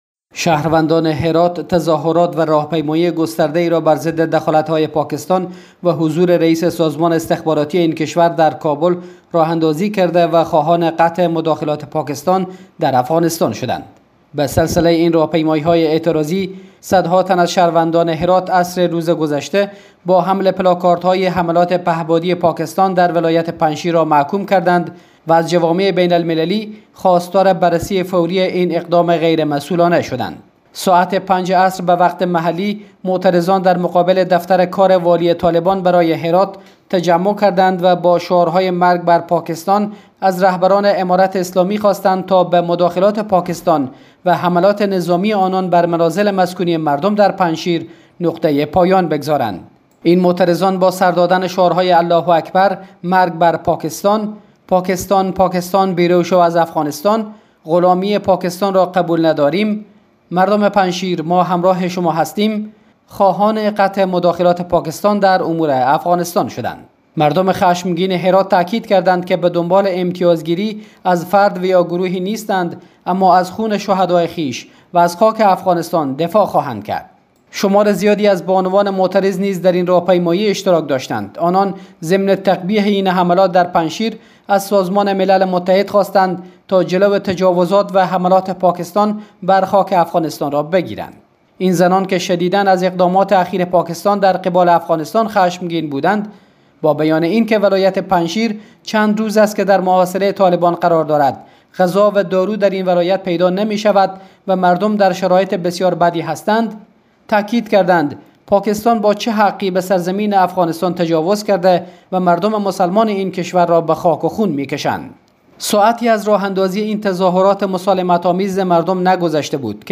به گزارش خبرنگار رادیودری، ساعت 5 عصر روز سه شنبه معترضان در مقابل دفتر کار والی طالبان برای ولایت هرات تجمع کردند و با شعارهای مرگ بر پاکستان و مرگ بر طالبان، از رهبران امارت اسلامی خواستند تا به مداخلات پاکستان و حملات نظامی آنان بر منازل مسکونی مردم در پنجشیر نقطه پایان بگذارند.